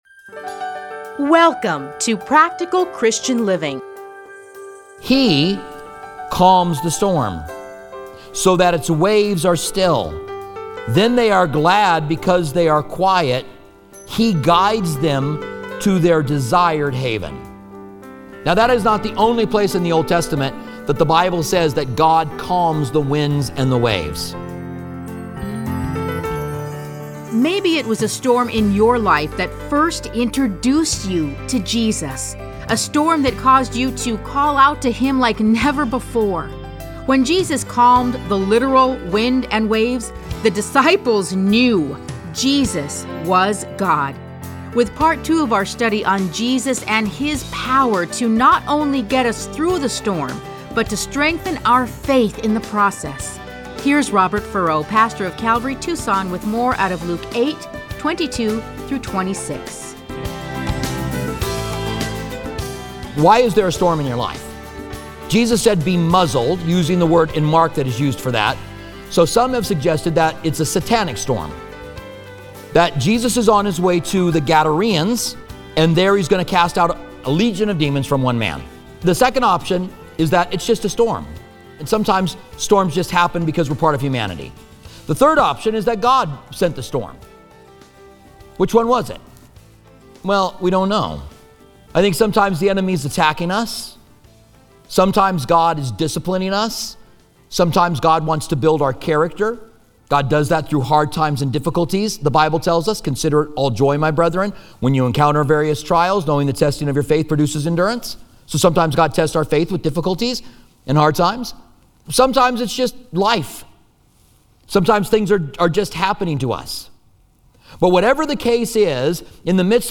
Listen to a teaching from Luke 8:22-26.